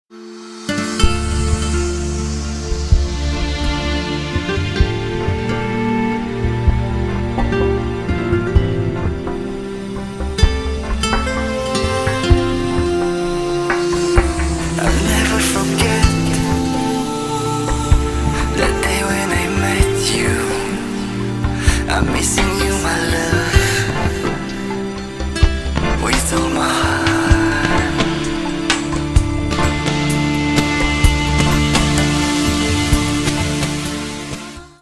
• Качество: 160, Stereo
поп
гитара
chillout